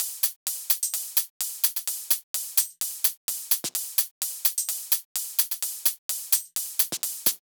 VDE1 128BPM Full Effect Drums 3.wav